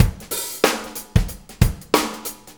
Shuffle Loop 28-08.wav